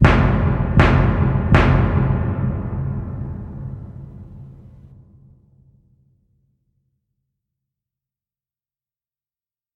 Звуки стука в дверь
Жуткий стук в дверь (звук)